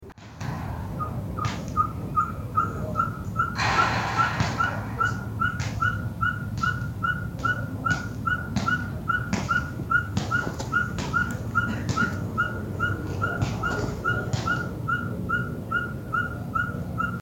Ferruginous Pygmy Owl (Glaucidium brasilianum)
Grabado a la 1 AM frente a la plaza "Las Toscaneras".
Location or protected area: Villa Real
Condition: Wild
Certainty: Recorded vocal